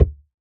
Minecraft Version Minecraft Version snapshot Latest Release | Latest Snapshot snapshot / assets / minecraft / sounds / block / packed_mud / step2.ogg Compare With Compare With Latest Release | Latest Snapshot